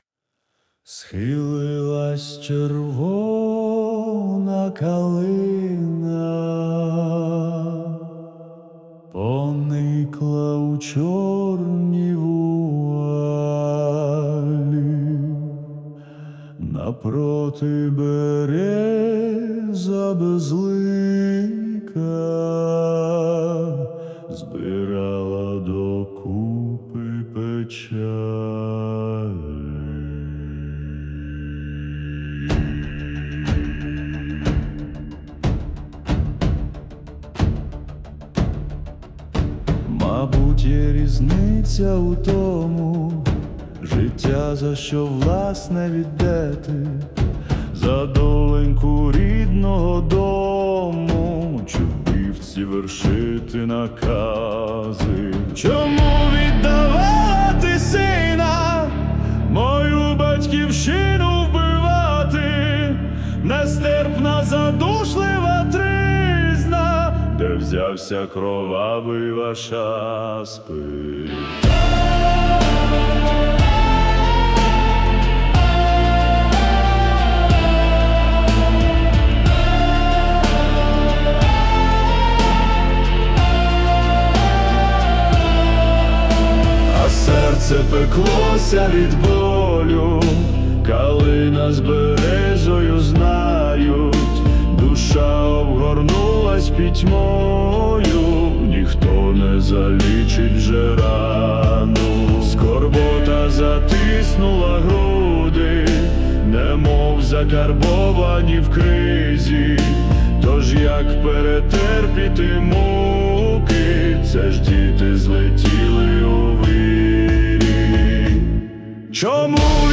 Музична композиція створена за допомогою SUNO AI
Зворушлива пісня, наче, крик душі, який не залишає байдужим!